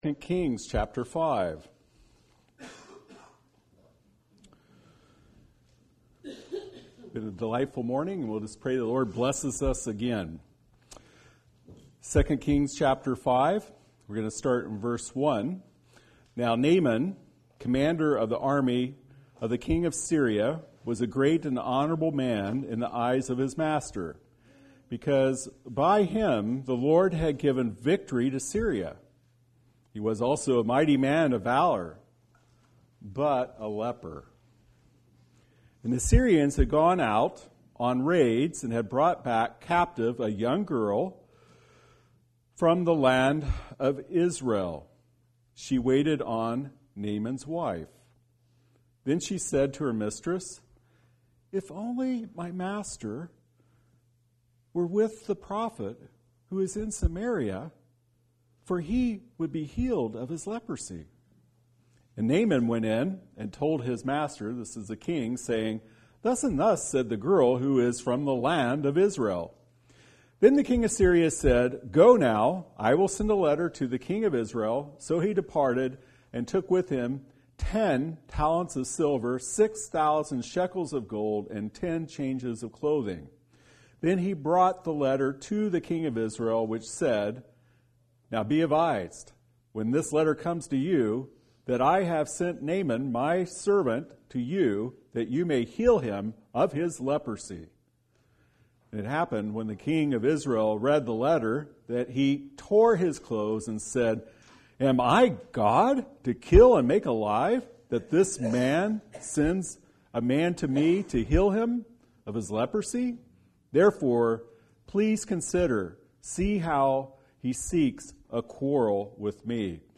Listen to Weekly Message
Series: Spring Bible Conference